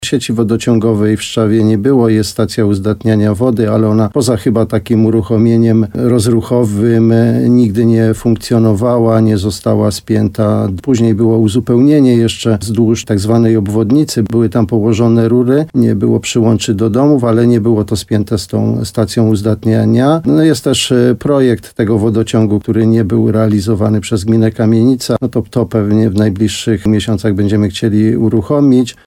– Zrobiliśmy już przegląd dotychczasowej infrastruktury przejętej od gminy Kamienica – mówi w programie Słowo za Słowo wójt gminy Szczawa Janusz Opyd.